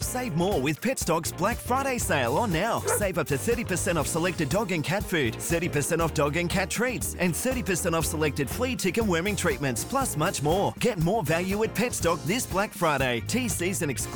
Here are some Black Friday audio ad samples that were heard across the world this year: